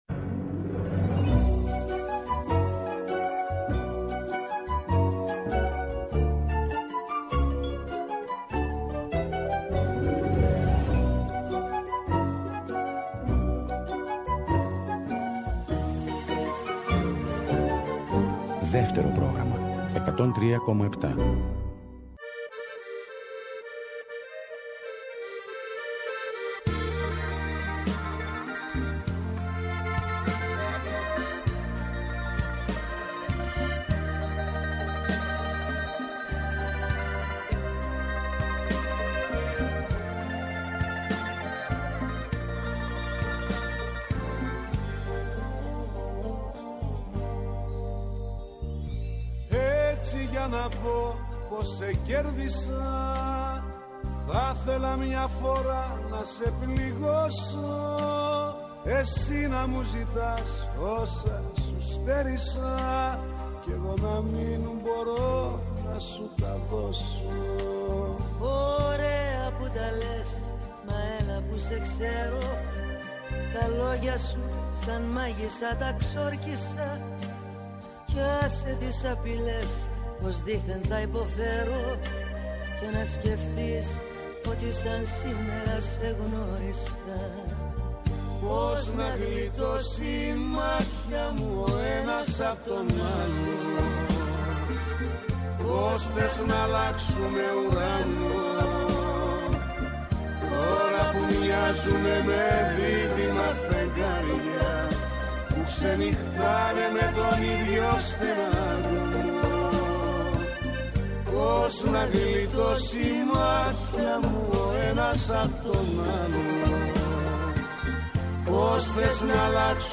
Στο “Μελωδικό Αντίδοτο” oι καινούριες μουσικές κάνουν παρέα με τις παλιές αγαπημένες σε μια ώρα ξεκούρασης καθώς επιστρέφουμε από μια κουραστική μέρα.